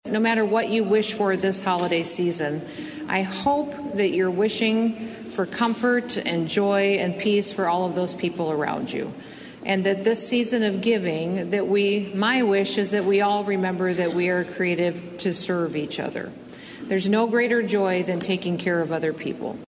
The governor then expanded on what this year’s “Winter Wishes” theme means to her.
South Dakota Governor Kristi Noem expands on the “Winter Wishes” theme for this year’s Christmas at the Capitol Grand Tree Lighting Ceremony Nov. 23, 2021.